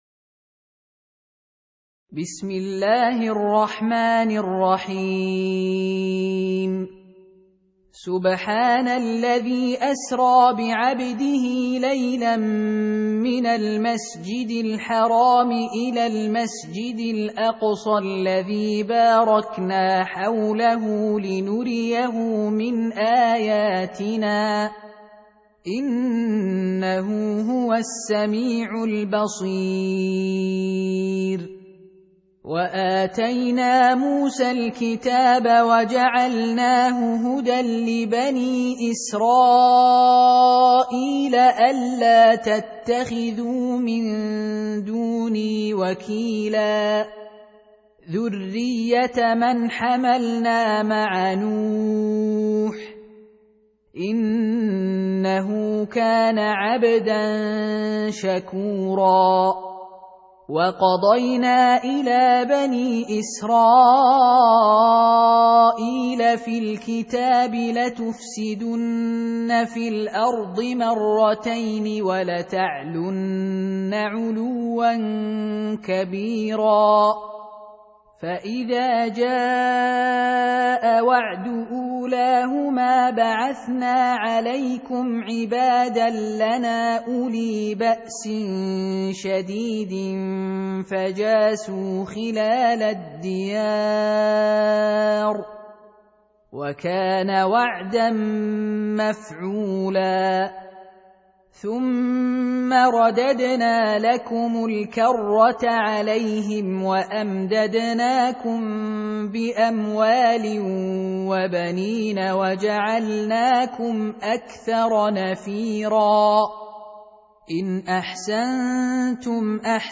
Surah Repeating تكرار السورة Download Surah حمّل السورة Reciting Murattalah Audio for 17. Surah Al-Isr�' سورة الإسراء N.B *Surah Includes Al-Basmalah Reciters Sequents تتابع التلاوات Reciters Repeats تكرار التلاوات